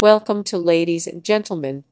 Linear-Spectrogram은 Griffin-Lim Algorithm이라는 음성 재구성 알고리즘을 통해서 음성으로 변환